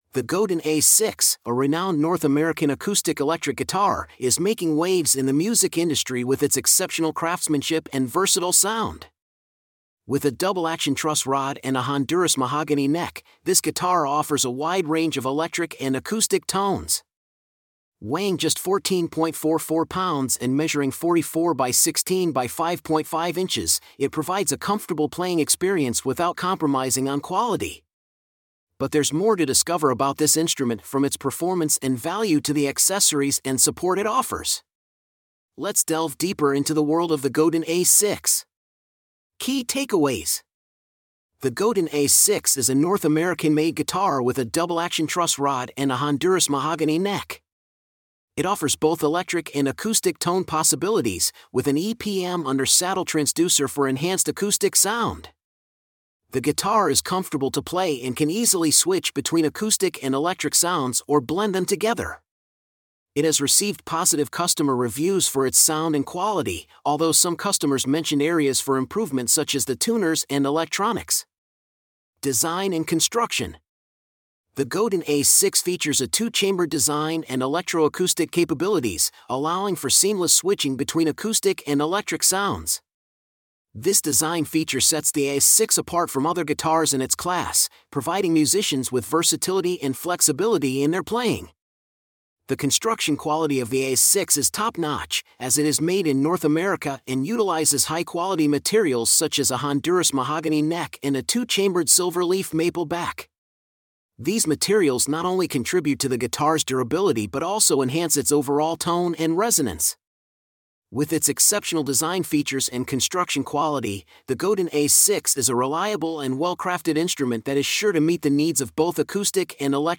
Godin A6 Review.mp3